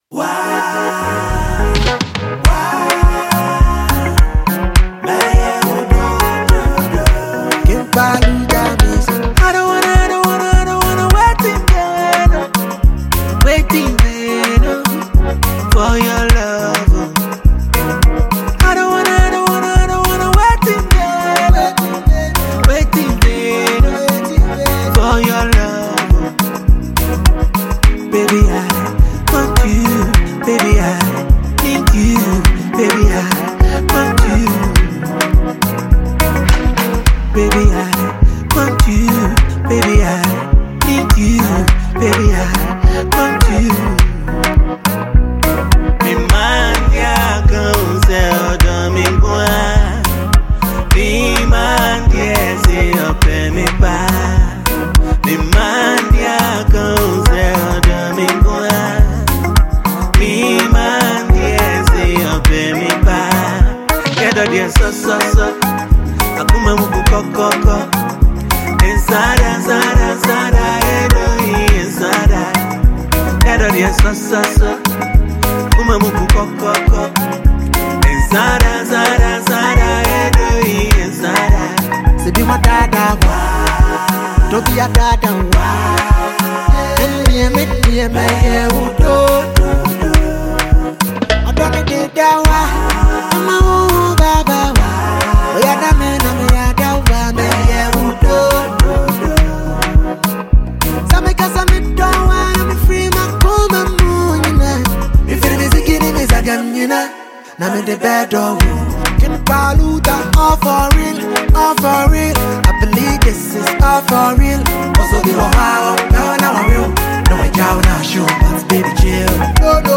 masterful blend of Ghanaian highlife and modern rap
smooth vocals